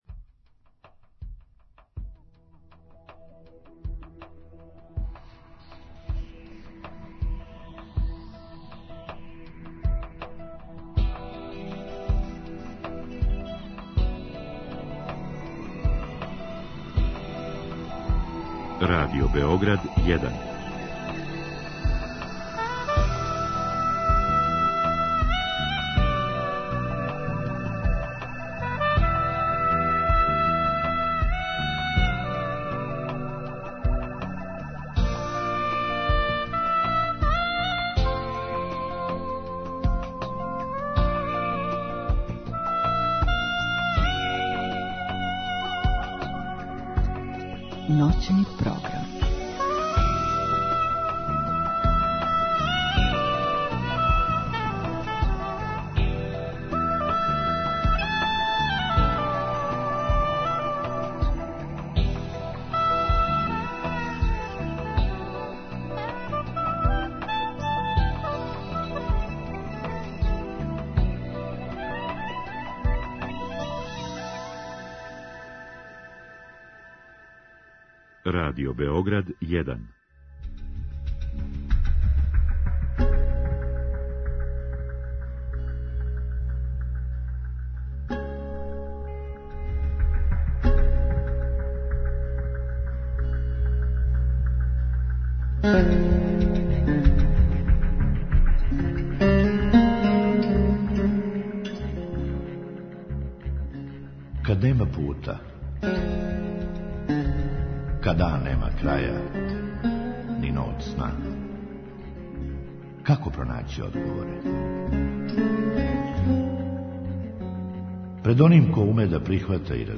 У другом сату слушаоци могу поставити питање гошћи у директном програму или путем Инстаграм странице емисије.